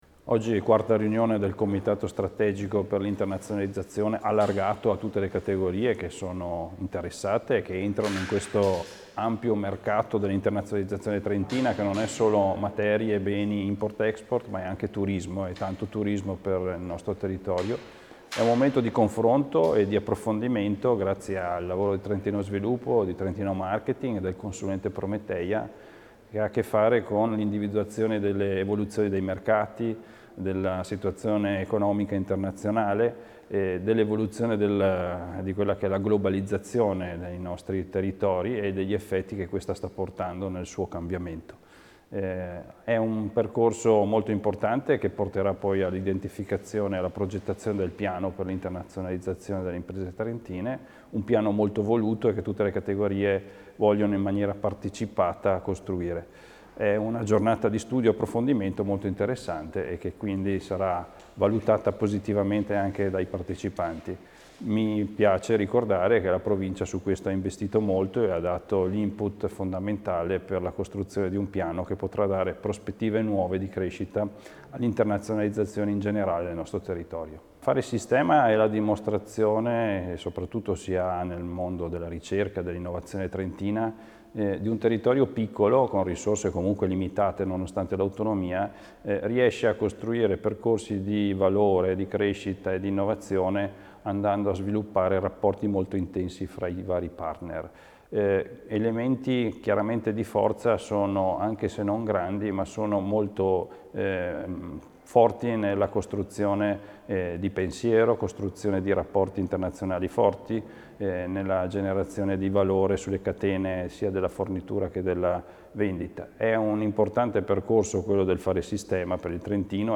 int_Achille_Spinelli.mp3